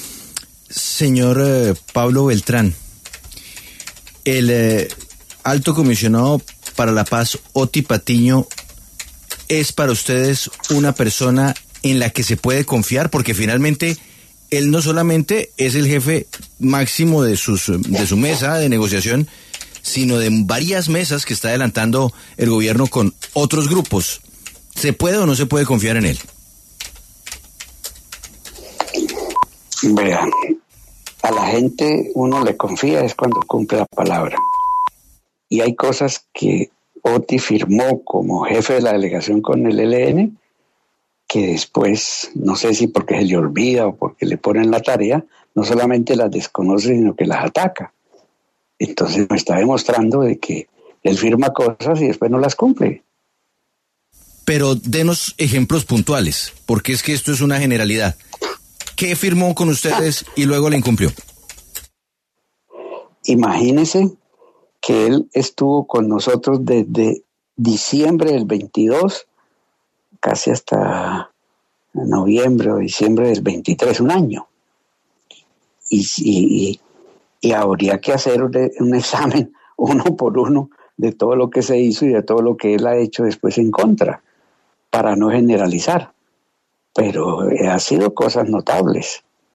Ante la noticia de que las delegaciones del ELN y del Gobierno Nacional retomarán los diálogos de paz, La W conversó con Pablo Beltrán, jefe negociador del Ejército de Liberación Nacional, quien arremetió contra el alto comisionado para la Paz, Otty Patiño, por, según él, incumplimientos de puntos firmados en la mesa.